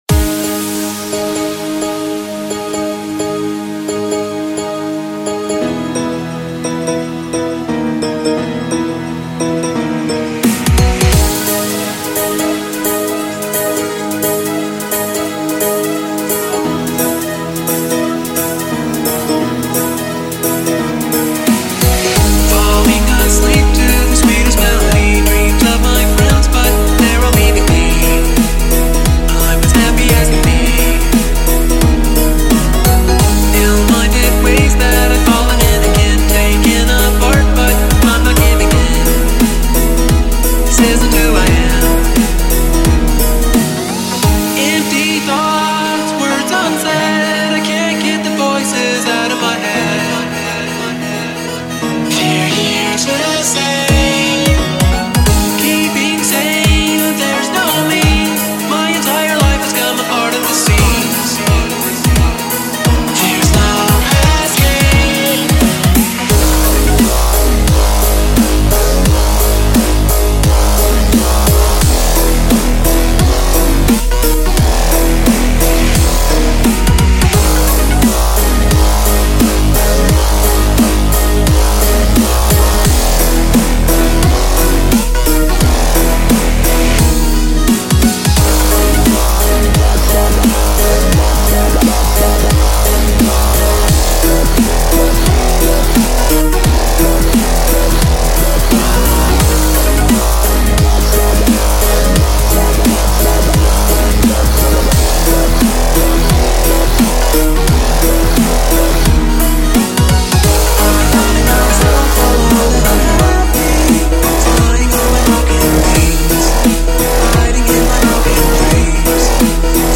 Genre: Drumstep Bpm:174